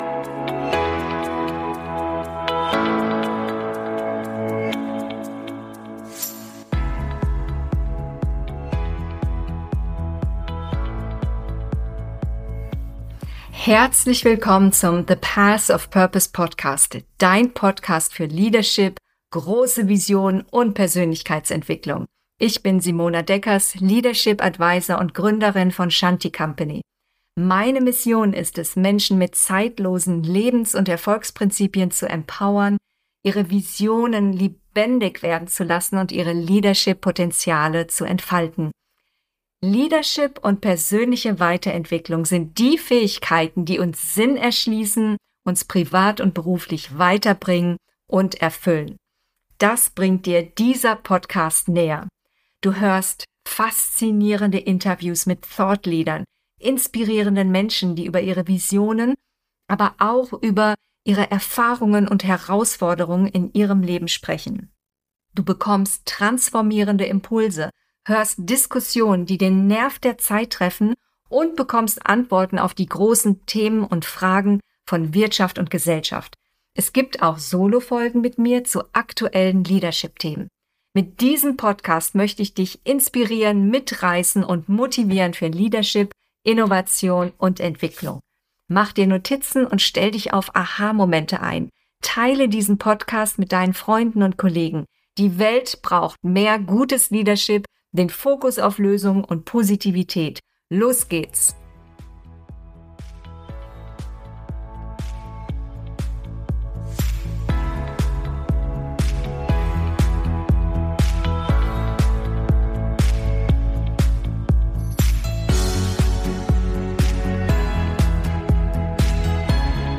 Leadership: Türen öffnen für andere mit Haltung und Mission - Interview